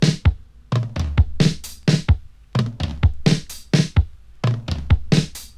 • 86 Bpm Modern Breakbeat Sample D# Key.wav
Free drum loop - kick tuned to the D# note. Loudest frequency: 789Hz
86-bpm-modern-breakbeat-sample-d-sharp-key-R6F.wav